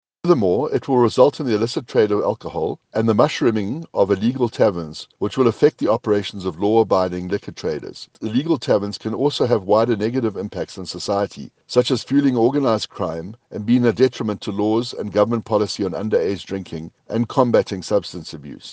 The DA’s, Patrick Atkinson, says this decision will ensure that entrepreneurs will not be able to start any new businesses in the sector: